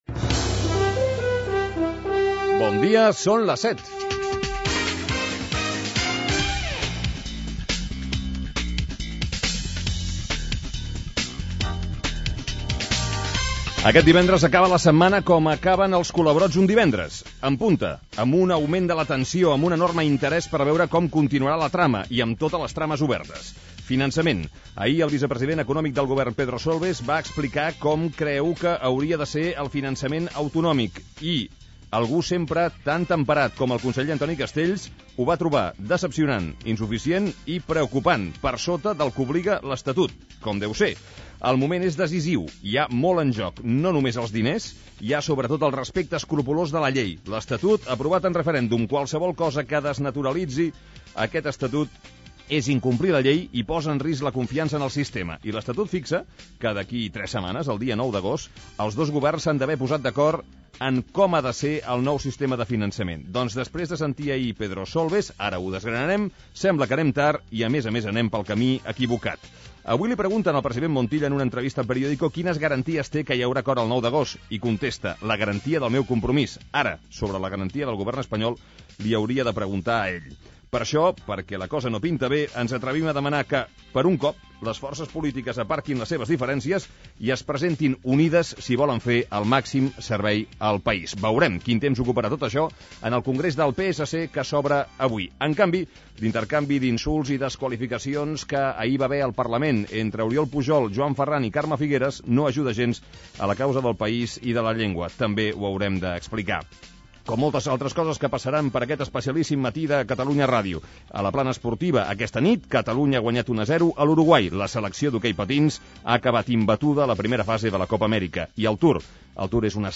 Ultima edició del programa presentada per Antoni Bassas,
Inici de la primera hora. Salutació,"Les notícies del matí (el nou finançament de Catalunya), indicatiu del programa , informació meteorològica, informació del trànsit (RACC), publicitat, hora "Ràdio cafè" (Adolfo Suárez, Jessica Parker), hora, publicitat, indicatiu del programa.
Info-entreteniment
FM